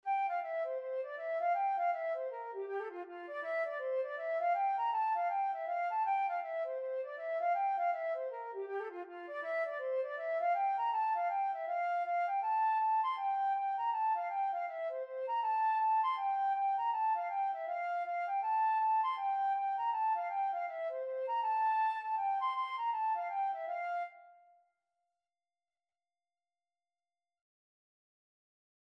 Traditional Music of unknown author.
2/2 (View more 2/2 Music)
F major (Sounding Pitch) (View more F major Music for Flute )
Flute  (View more Easy Flute Music)
Traditional (View more Traditional Flute Music)